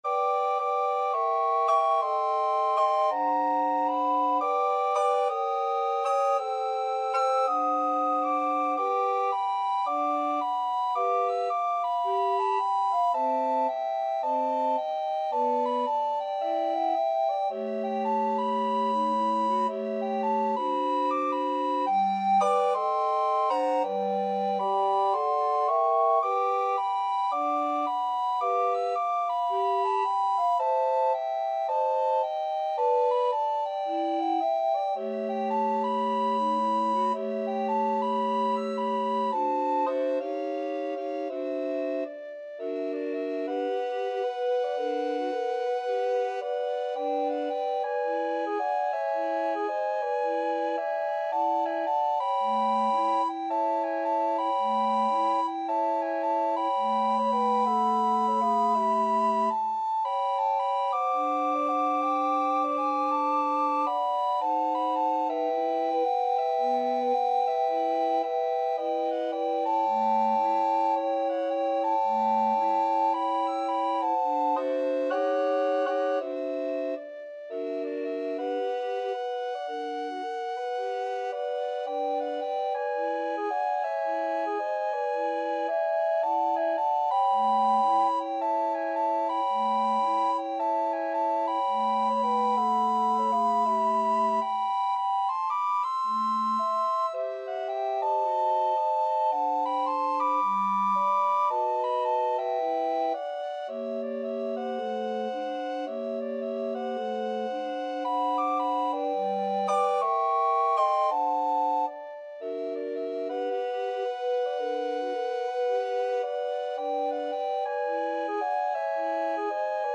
Soprano RecorderAlto RecorderTenor RecorderBass Recorder
~ = 110 Moderate swing
2/2 (View more 2/2 Music)
Recorder Quartet  (View more Easy Recorder Quartet Music)
Pop (View more Pop Recorder Quartet Music)